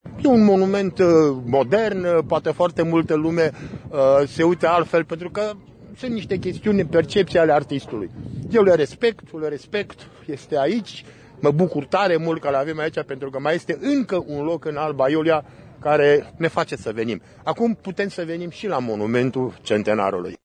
Primarul din Alba Iulia, Mircea Hava: